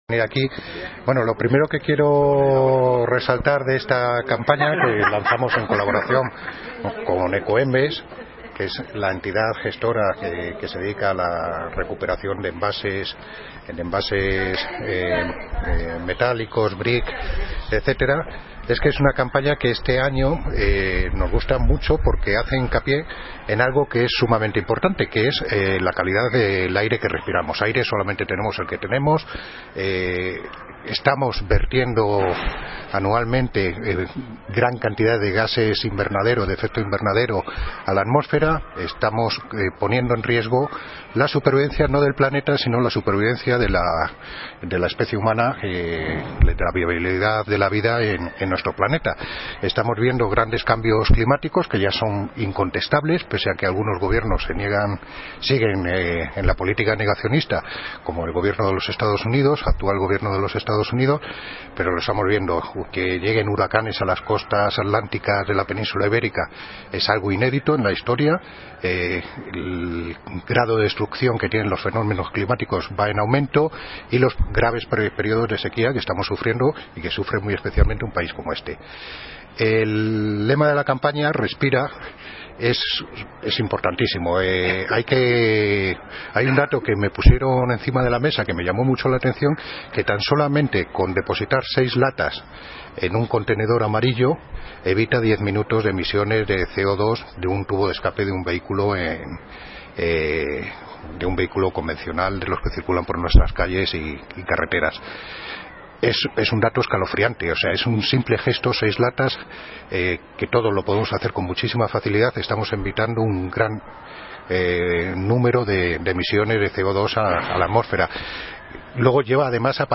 Audio - Miguel Angel Ortega (Concejal de Medio Ambiente, Parques, Jardines y Limpieza Viaria)